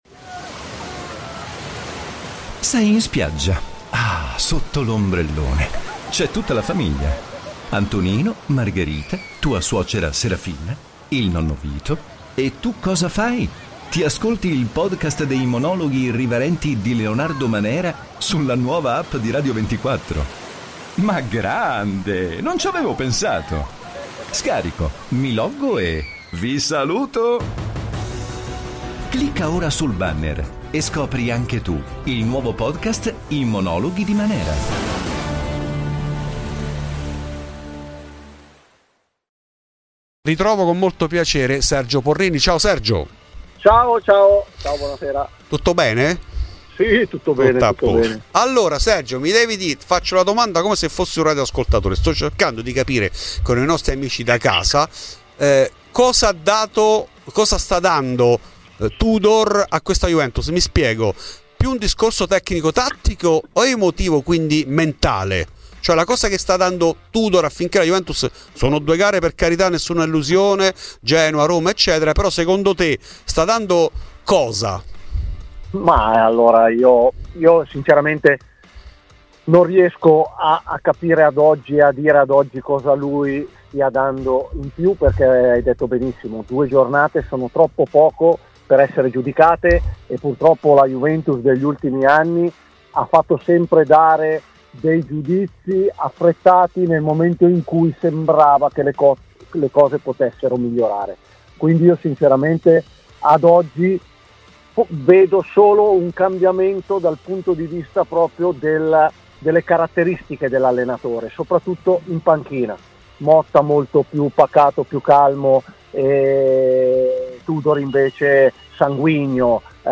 In ESCLUSIVA a Fuori di Juve Sergio Porrini. L'ex difensore della Vecchia Signora tra presente e futuro.